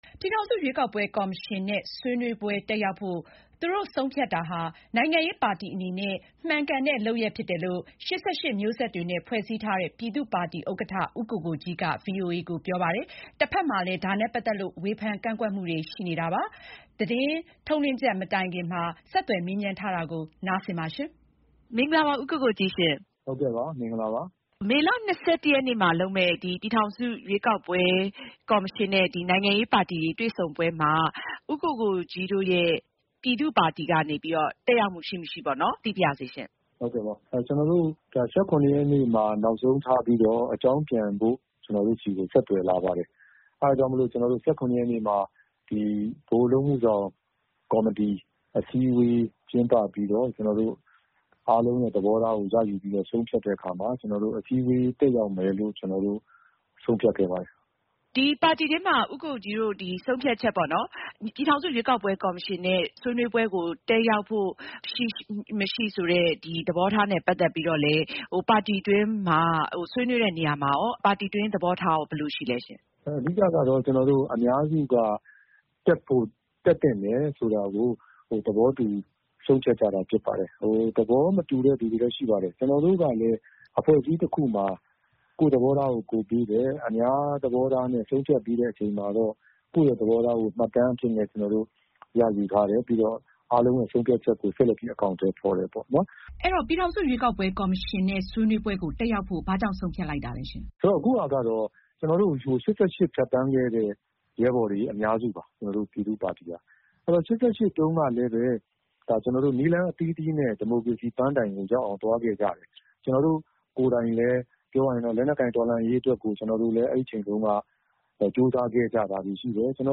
ရွေးကောက်ပွဲကော်မရှင် အစည်းအဝေး ပြည်သူ့ပါတီ ဘာကြောင့်တက်ရောက်မလဲ (ဦးကိုကိုကြီးနှင့် မေးမြန်းချက်)